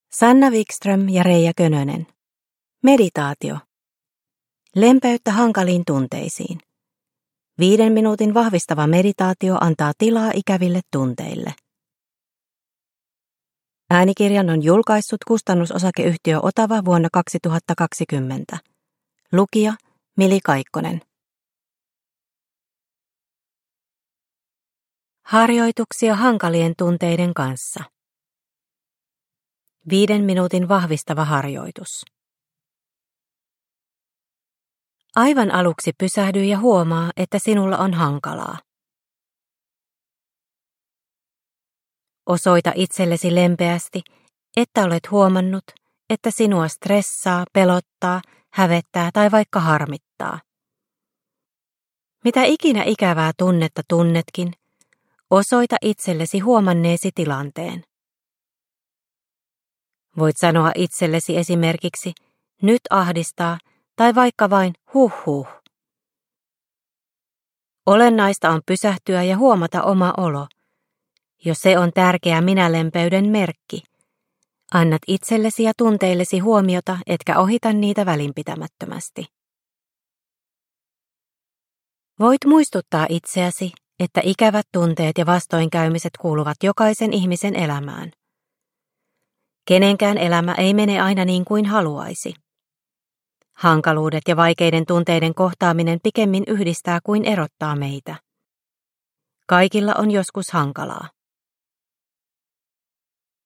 Meditaatio - Lempeyttä hankaliin tunteisiin – Ljudbok – Laddas ner